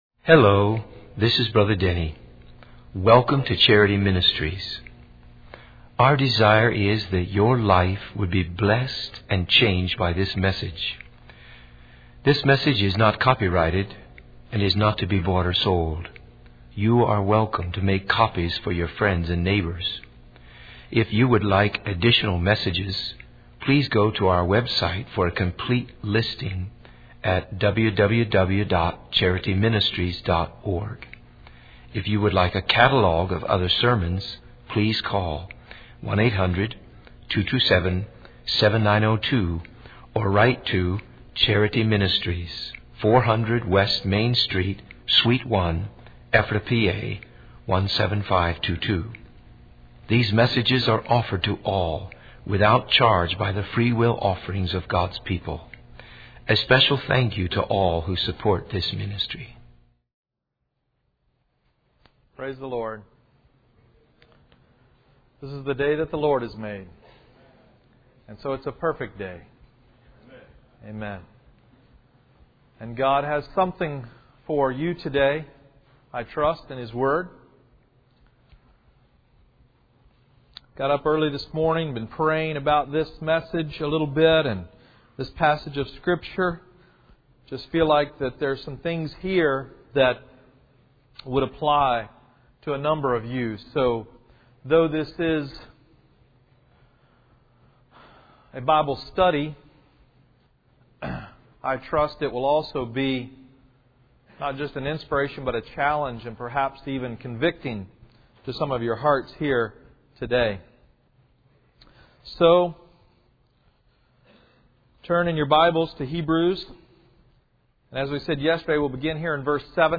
In this sermon, the preacher discusses the story of the children of Israel in the book of Numbers. He highlights the importance of belief and faith in God's promises.